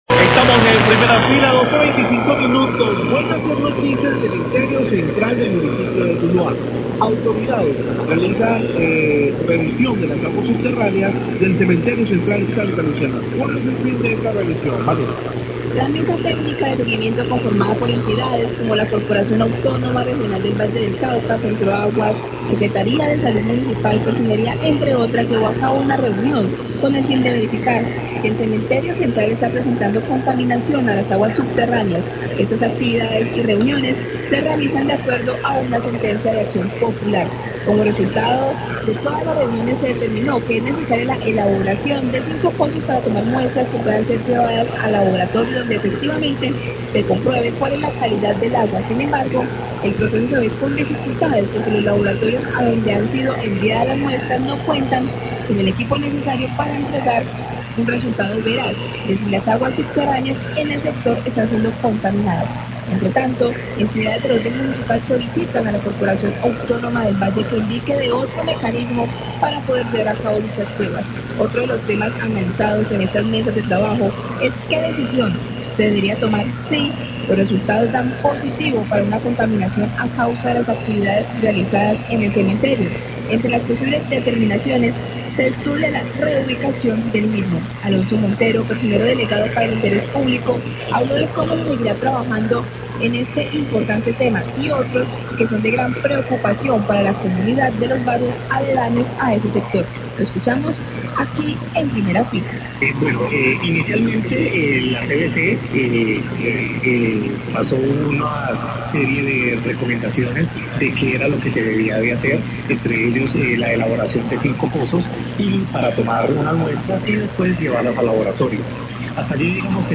Radio
Alonso Montero personero delegado para el interés público habla de cómo avanza este proceso de la posible contaminación del cementerio central de las aguas subterráneas en el municipio de Tuluá.